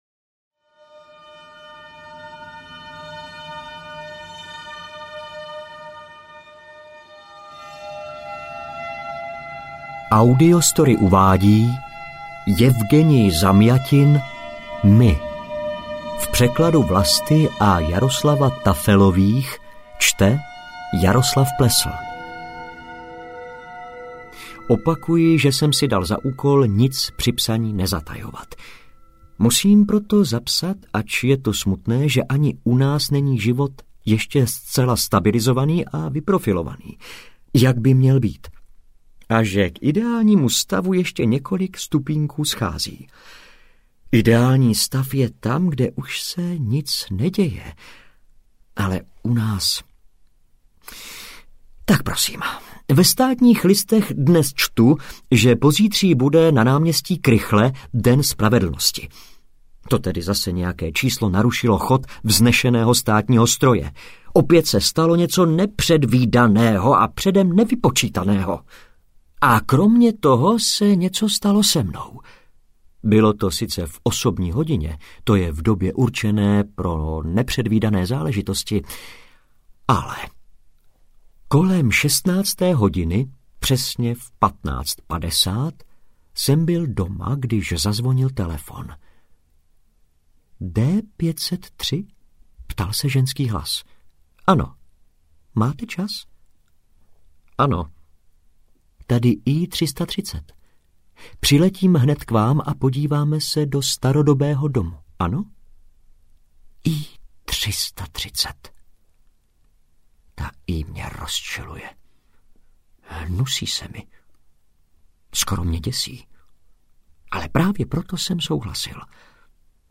MY audiokniha
Ukázka z knihy
• InterpretJaroslav Plesl